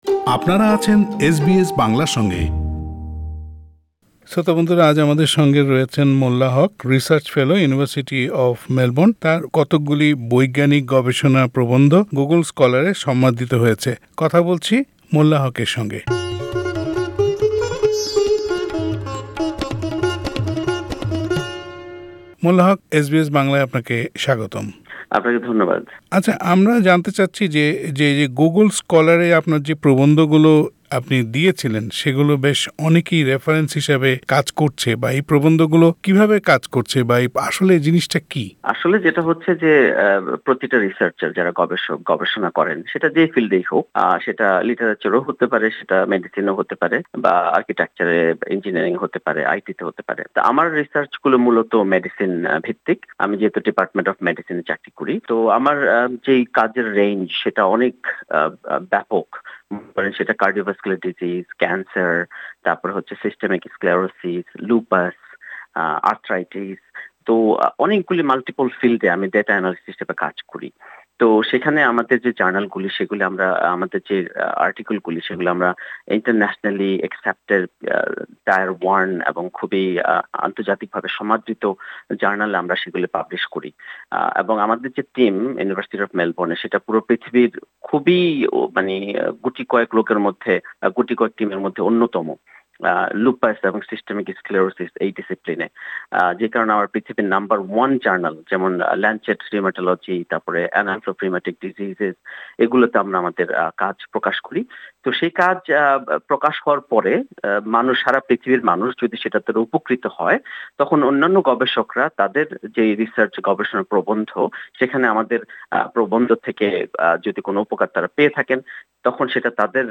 এস বি এস বাংলার সঙ্গে আলাপচারিতায় তিনি তার গবেষণা নিয়ে কথা বলেছেন।